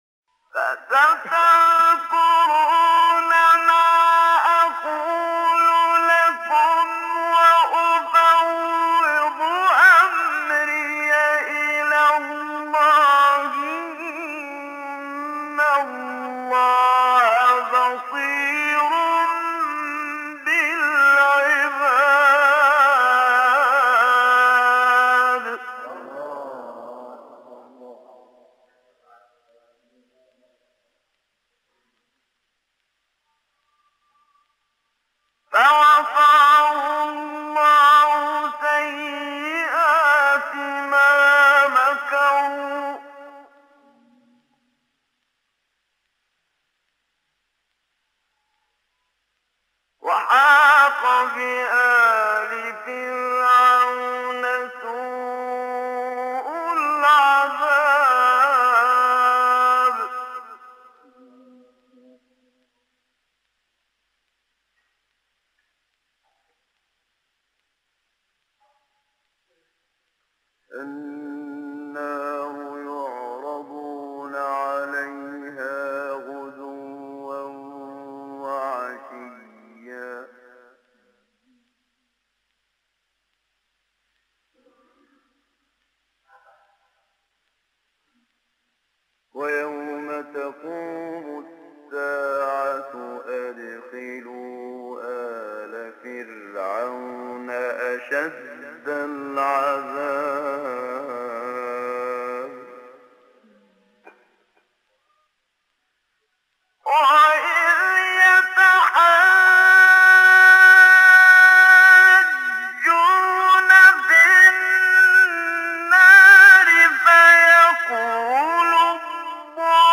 مقام نهاوند آیه 44-47 سوره غافر محمد صدیق منشاوی | نغمات قرآن | دانلود تلاوت قرآن